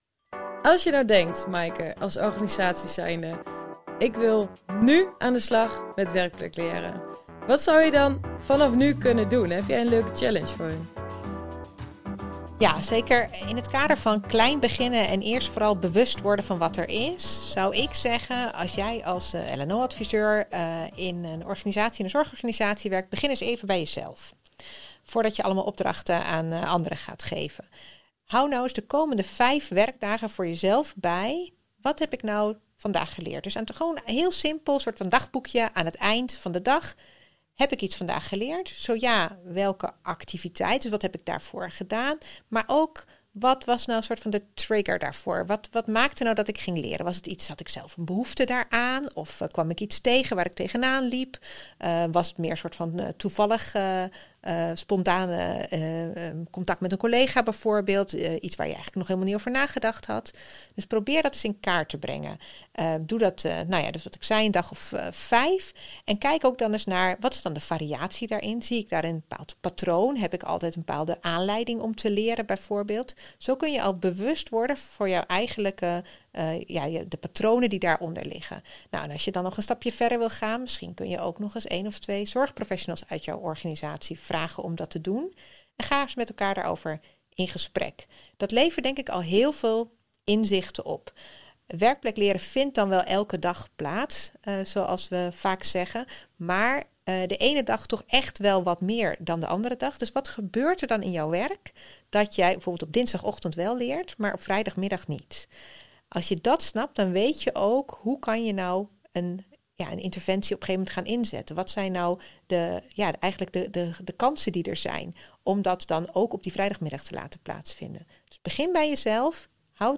Dagboek-Werkplekleren-de-uitleg.mp3